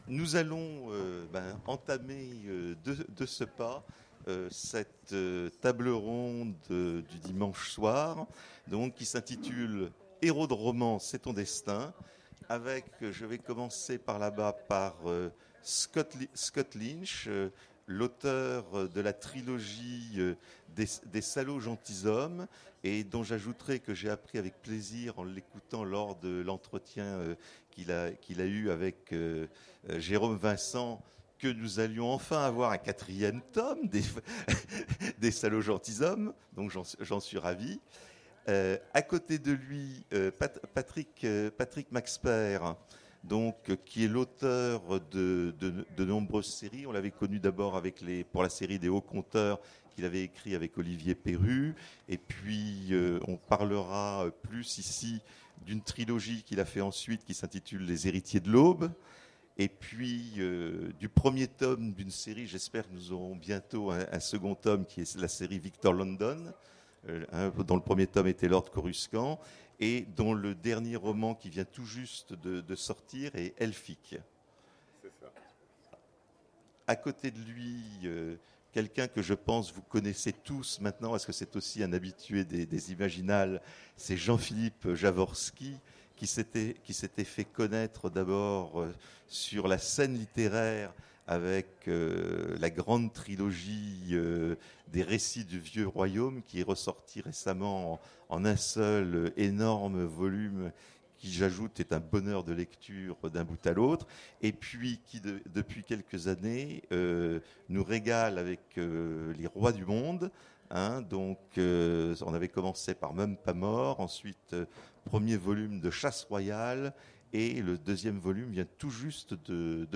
Imaginales 2017 : Conférence Héros de roman... C'est ton destin !